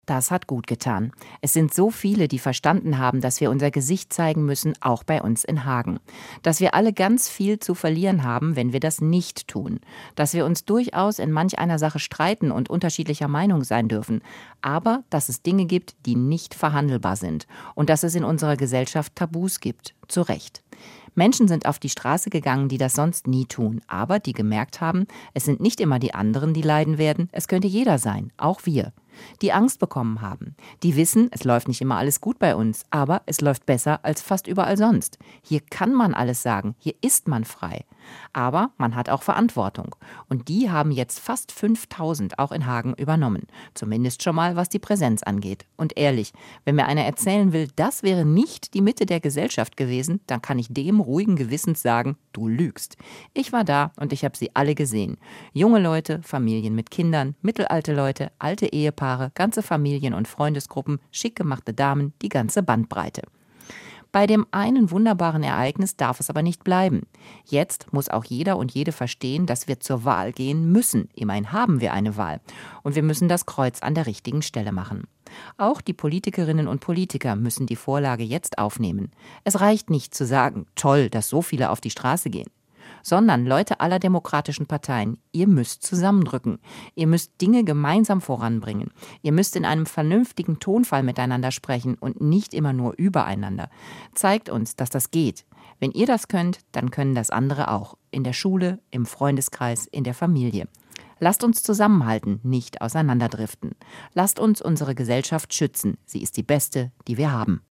kommentar-nach-der-demo.mp3